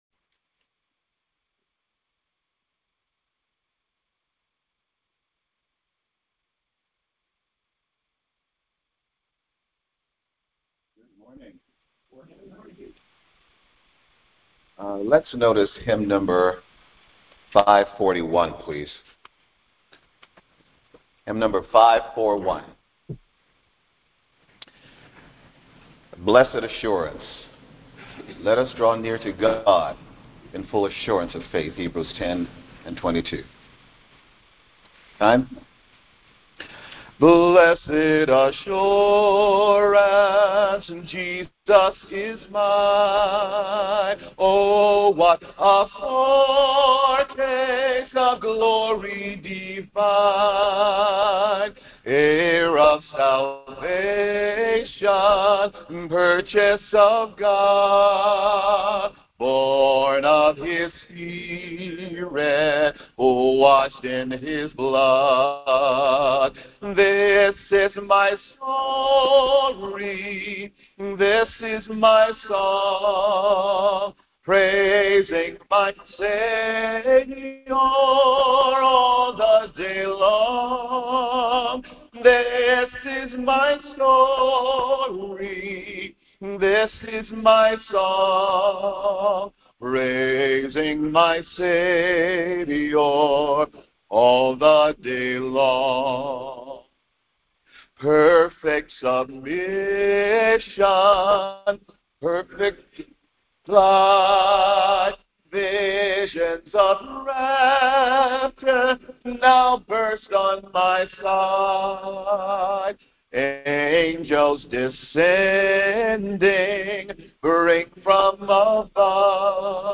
Sunday Morning Service 4.26.26